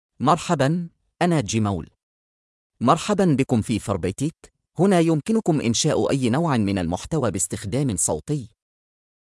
Jamal — Male Arabic (Morocco) AI Voice | TTS, Voice Cloning & Video | Verbatik AI
Jamal is a male AI voice for Arabic (Morocco).
Voice sample
Male
Jamal delivers clear pronunciation with authentic Morocco Arabic intonation, making your content sound professionally produced.